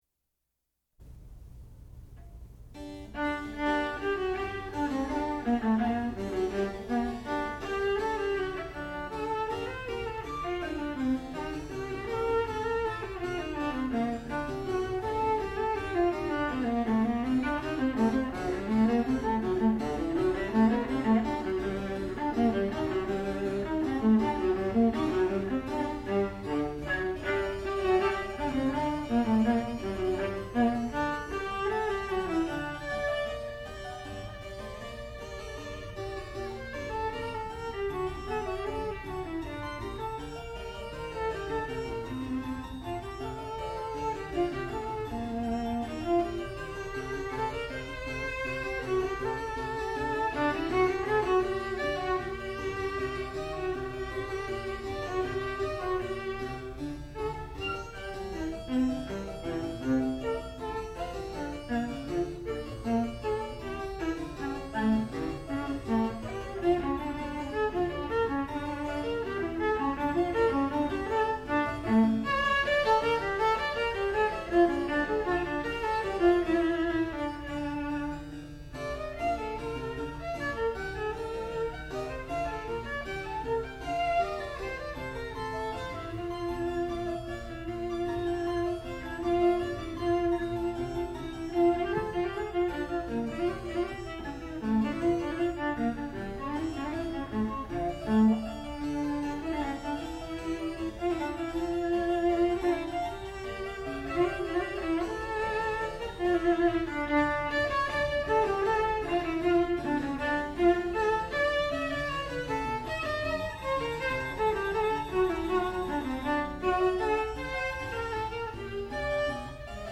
sound recording-musical
classical music
Advanced Recital